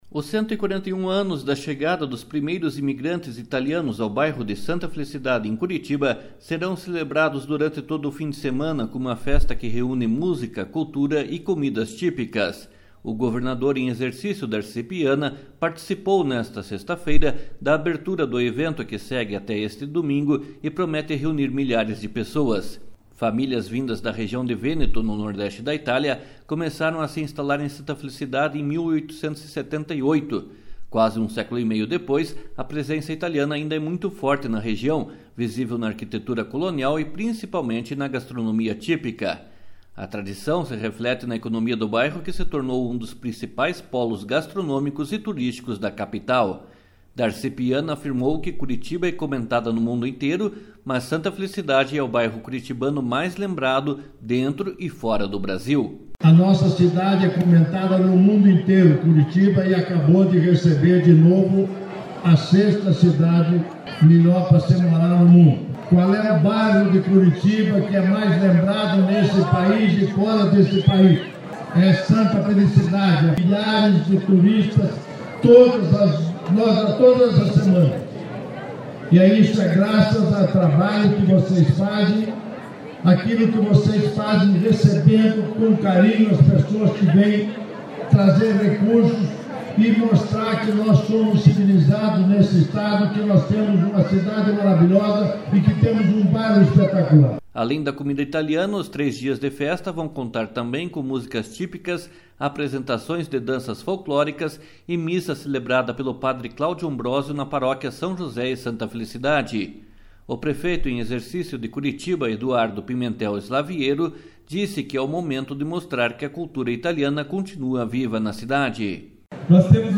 // SONORA DARCI PIANA //
// SONORA EDUARDO PIMENTEL SLAVIERO //.